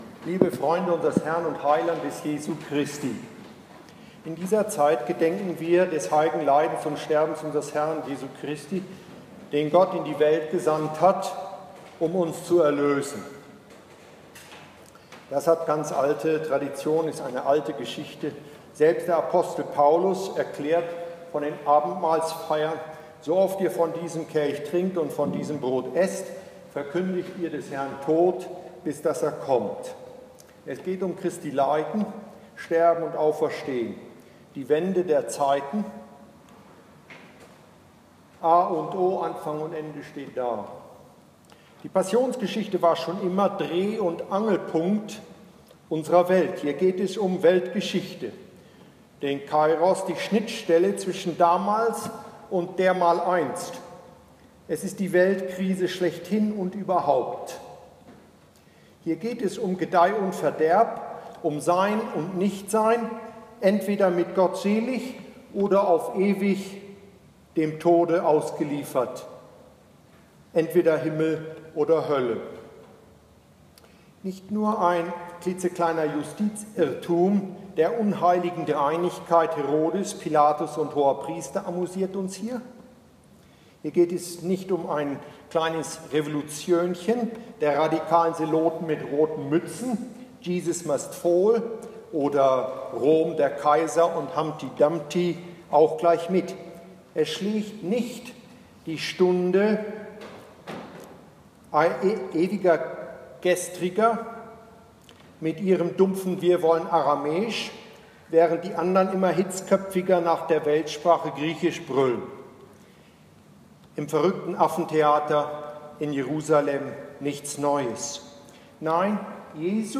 Passionsandacht in St.Pauls